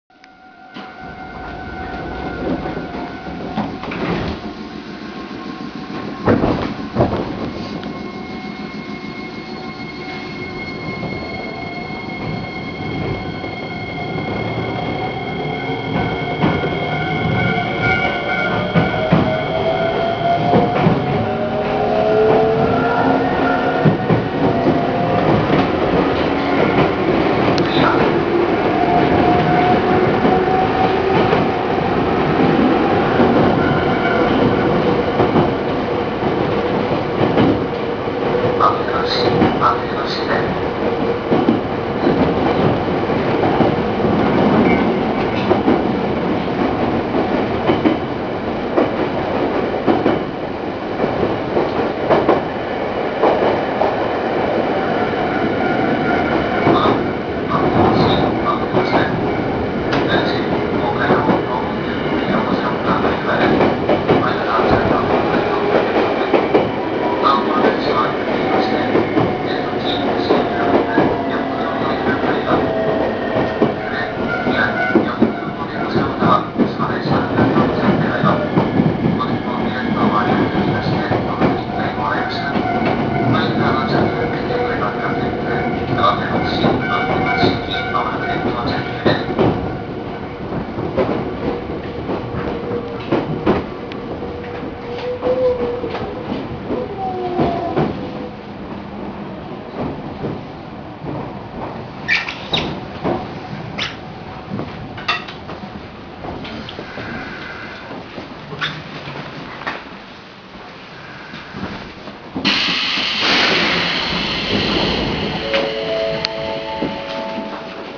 ・3000系走行音
【高浜線】大手町→松山市（1分58秒：641KB）
よくある東洋IGBTの走行音なのですが、若干音程が高い様な…。あまり速度を出す区間が無いので、ちょっと収録は難しいかもしれません。